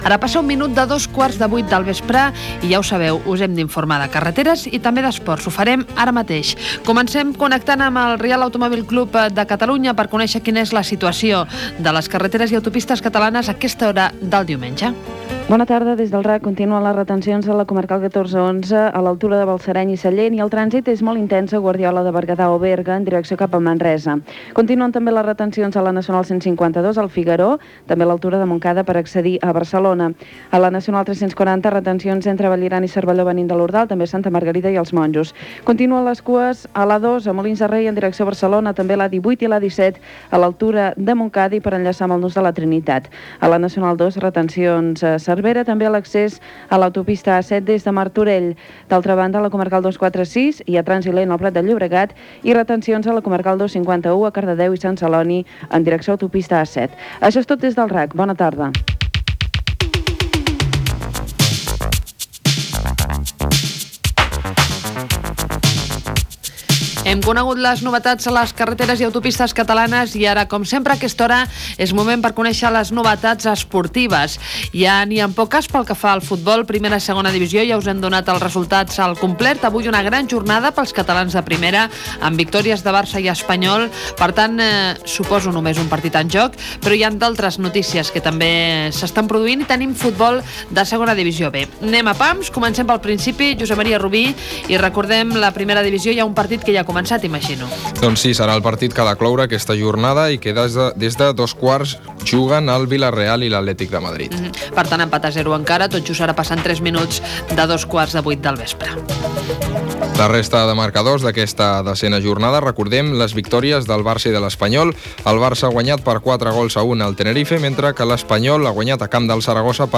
Hora, informació del trànsit des del Reial Automòbil Club de Catalunya (RACC), resultats dels partits de futbol masculí, travessa, partits de bàsquet masculí, indicatiu del programa i tema musical
Info-entreteniment
FM